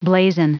Prononciation du mot blazon en anglais (fichier audio)
Prononciation du mot : blazon